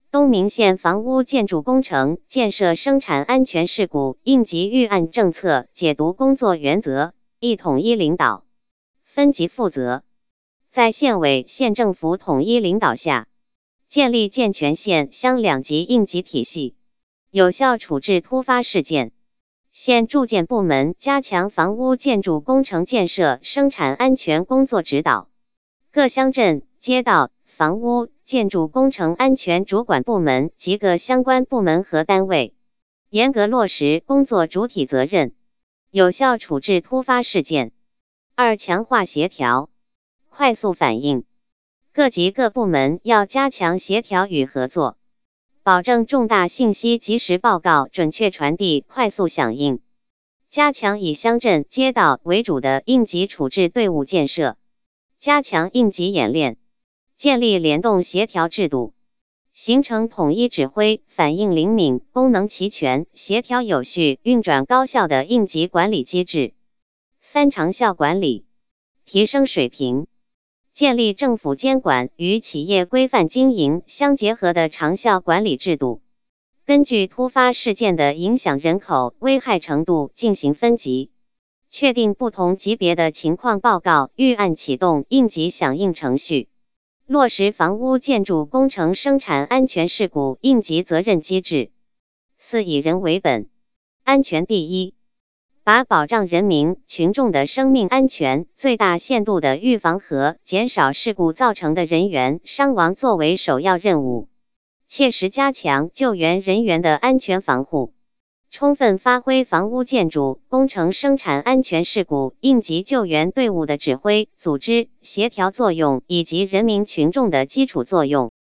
• 分　　类：音频解读